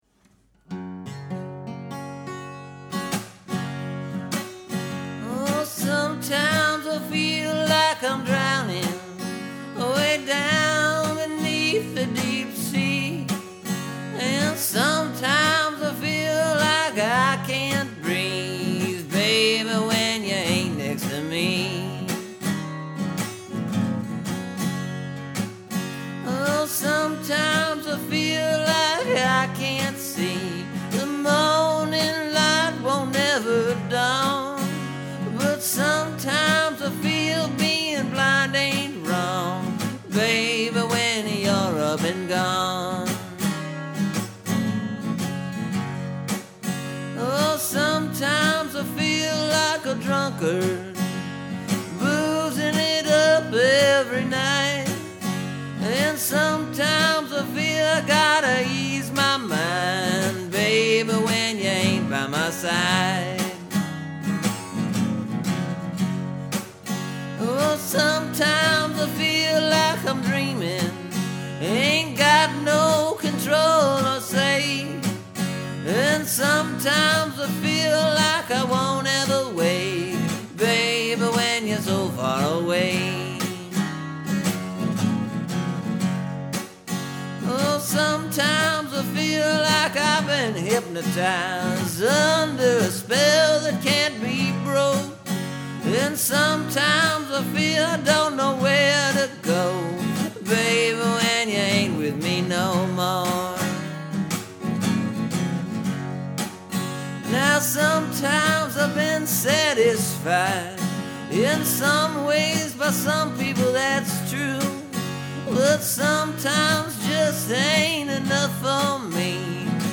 This one I wrote and recorded last night. It’s kinda got a country song feel to it, I think.